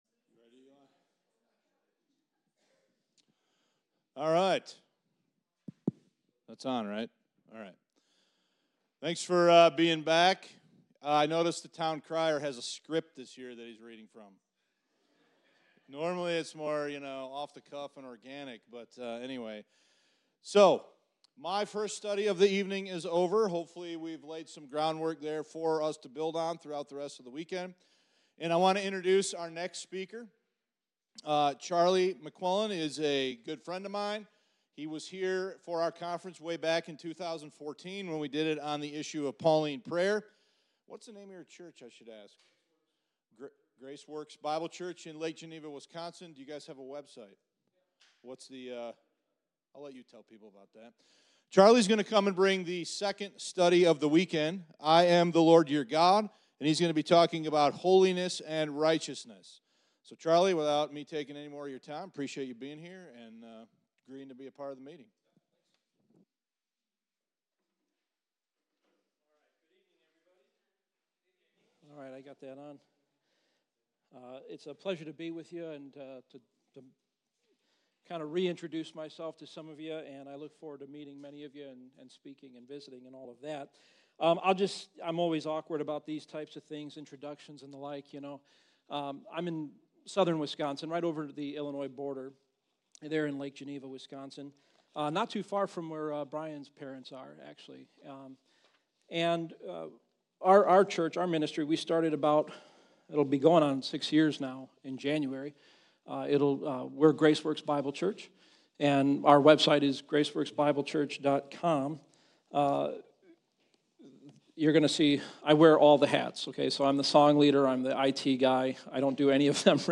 2024 West Michigan Grace Bible Conference } Understanding The Nature & Character of God The Father